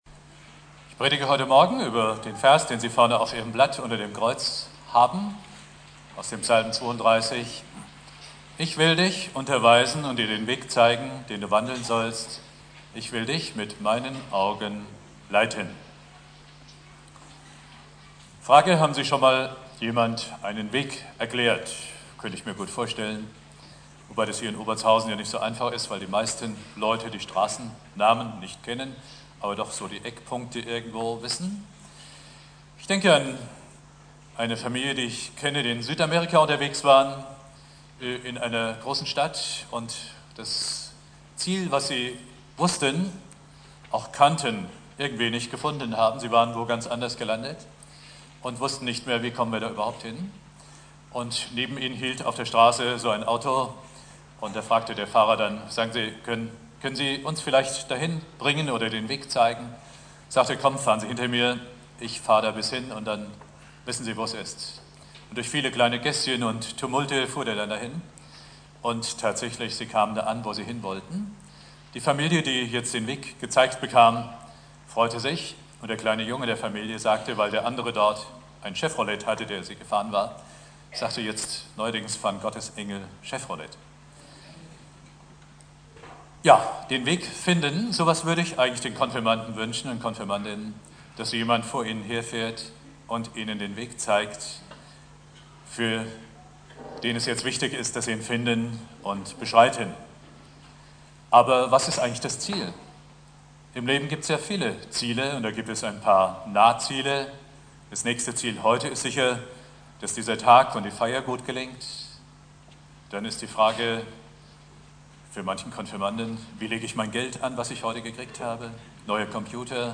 Predigt
Thema: (Konfirmation) Bibeltext: Psalm 32,8 Dauer